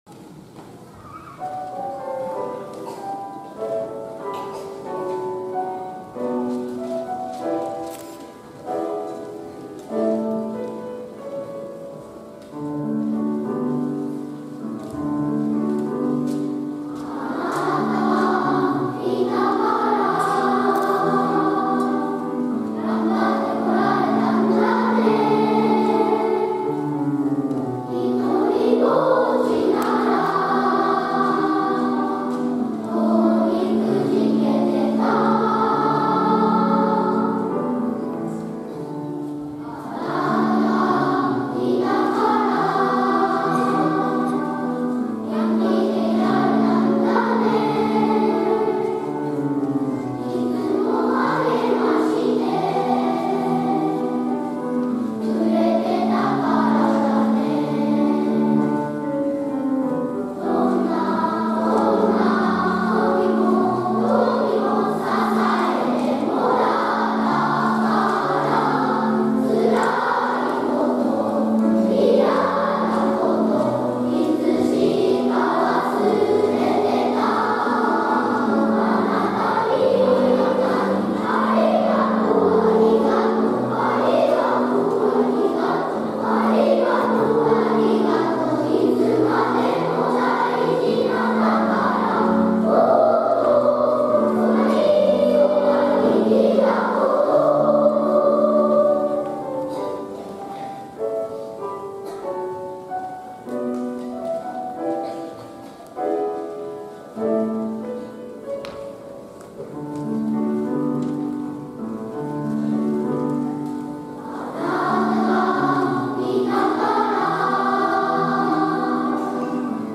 １・２・３年生の２部合唱。
１年生はソプラノを歌い、２・３年生はソプラノとアルトにわかれてハーモニーをつくります。